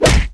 空手击中2zth070522.wav
通用动作/01人物/03武术动作类/空手击中2zth070522.wav
• 声道 單聲道 (1ch)